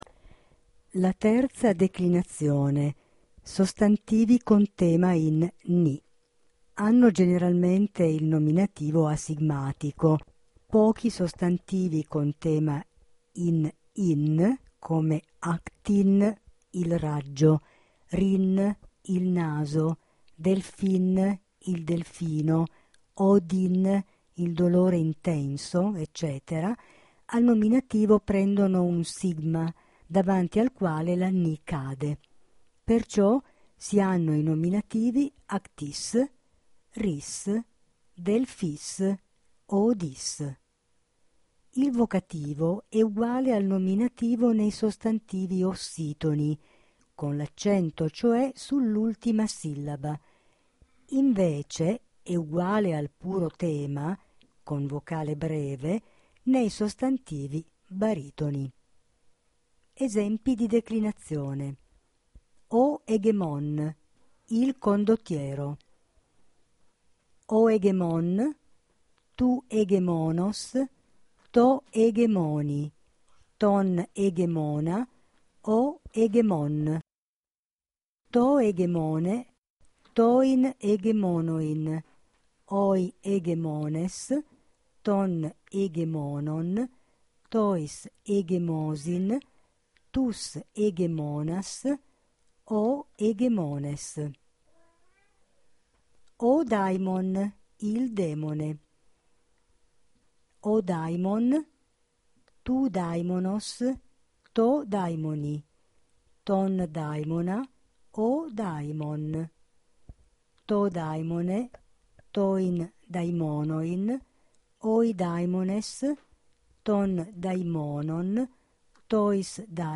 ) permette di sentire la lettura dei sostantivi della terza declinazione in nasale